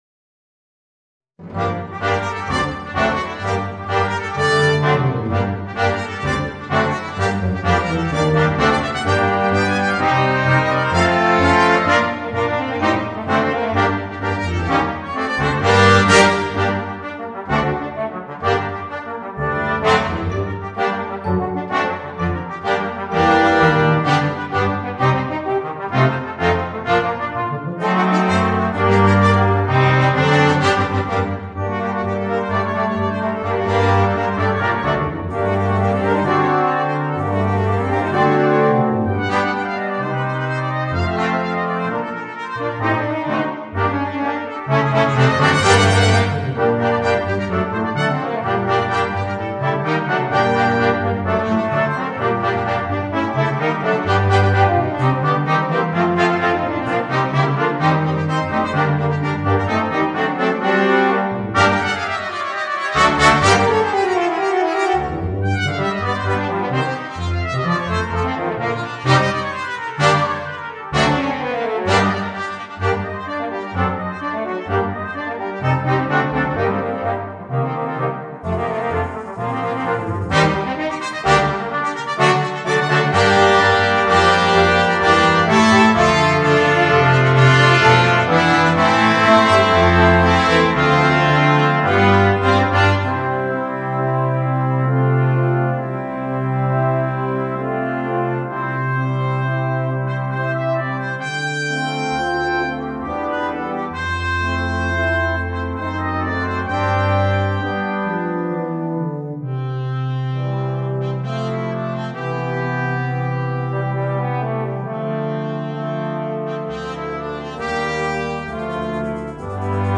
4 Trompeten, 2 Hörner, 2 Posaunen & 2 Tuben